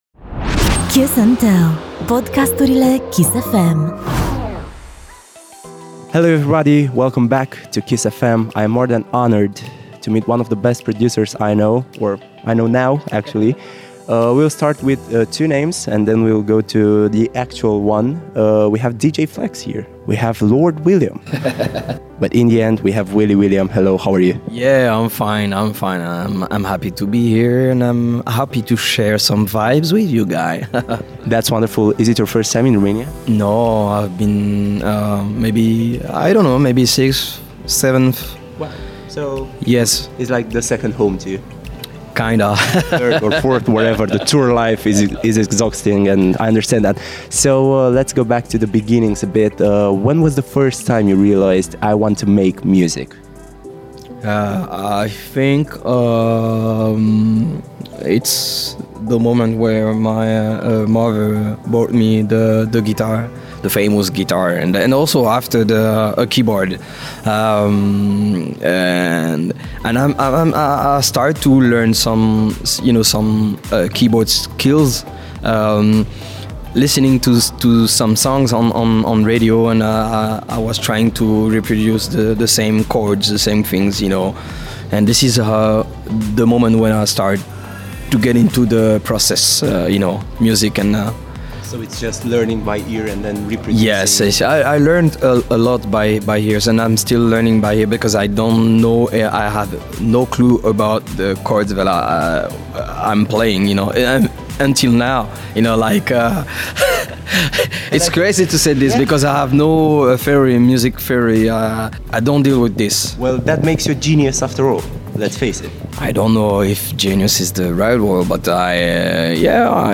Kiss FM - Kiss and Tell - Willy William- interviu